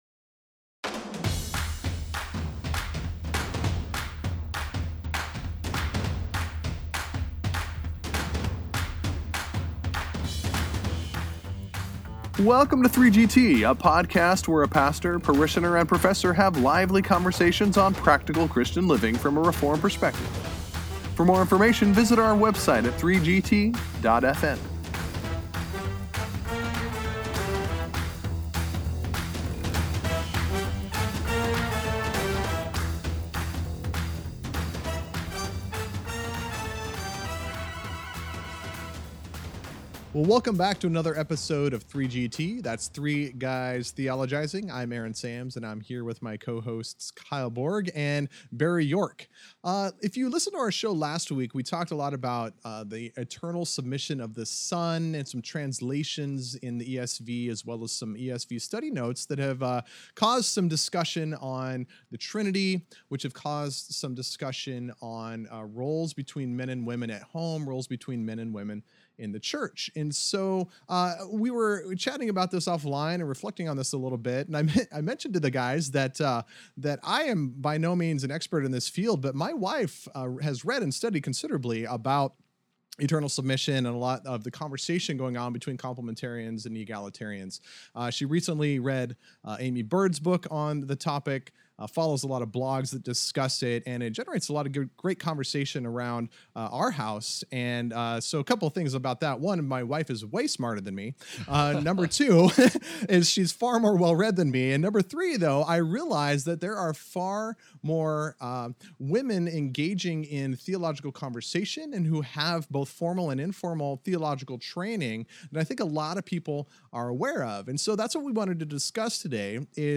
The guys discuss this trend and wrestle a bit with its implications.